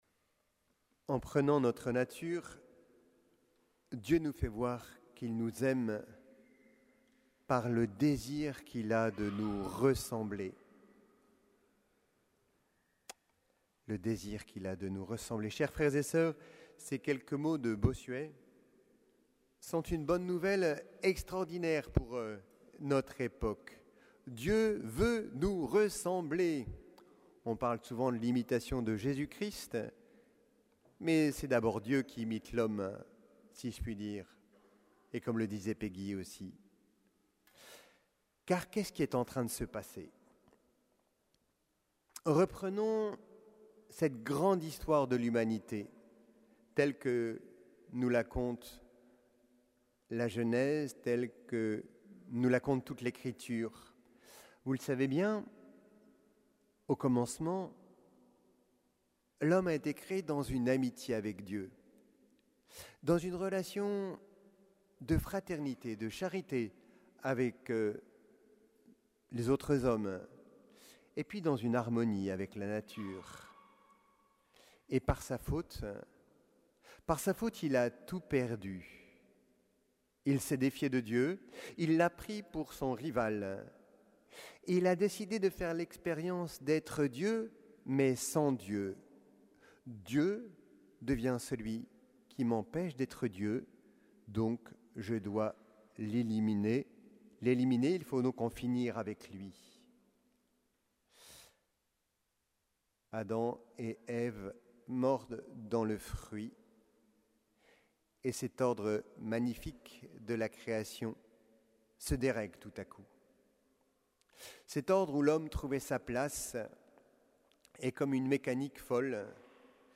Homélie de la messe de la Nativité du Seigneur (messe du jour)